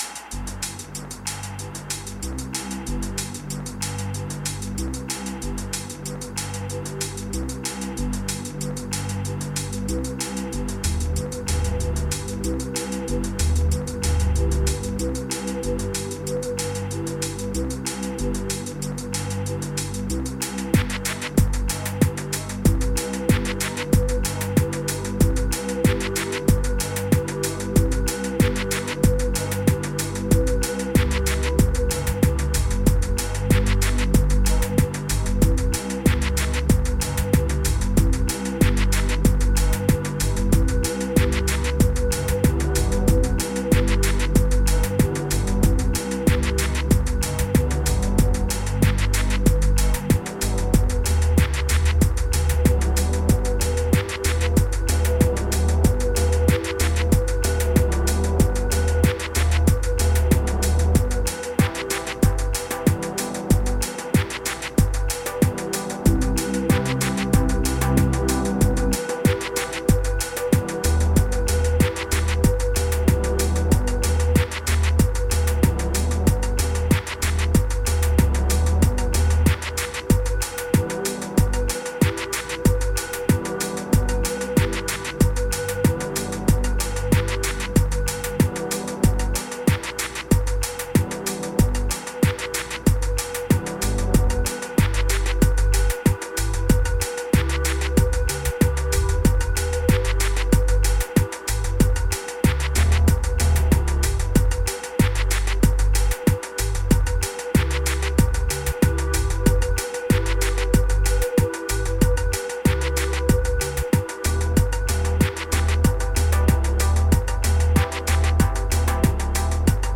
Prepare your subwoofer or your deep headz.
electronic dubity energism velo-city